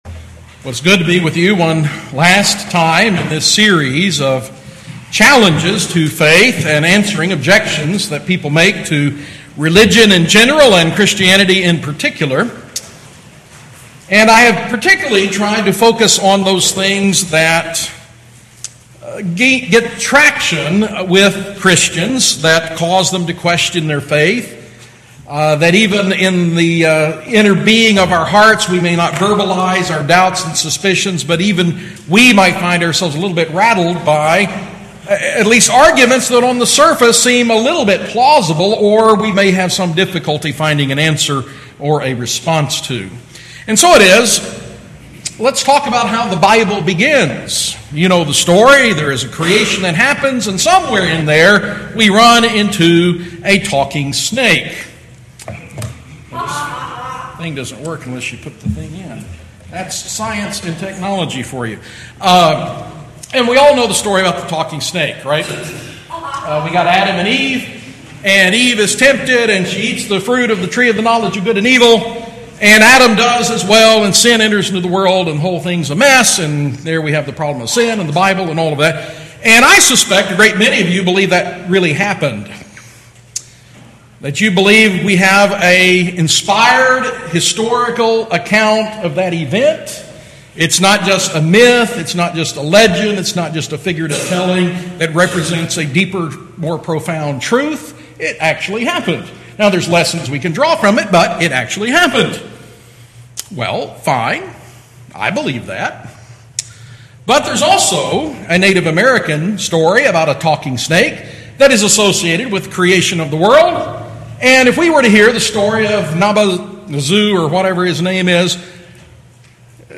Series: Answering Objections Against Christianity, Gettysburg 2016 Bible Forum